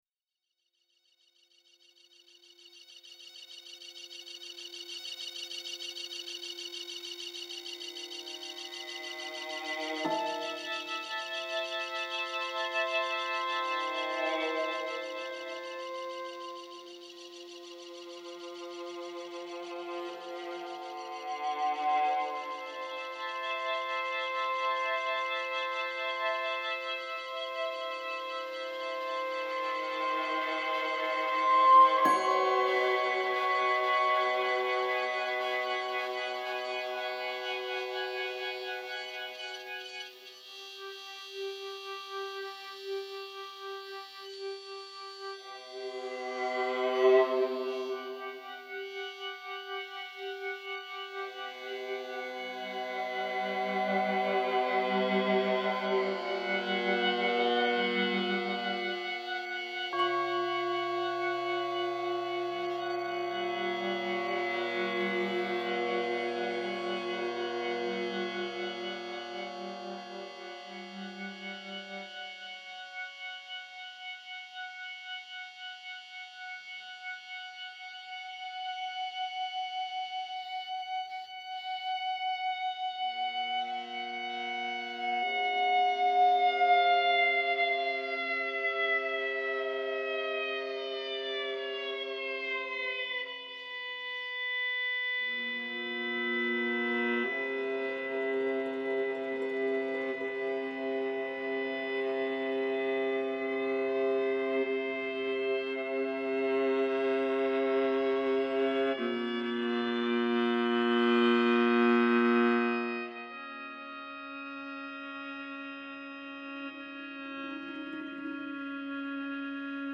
composed with electronic instruments and samples
viola
various percussion and plucked instruments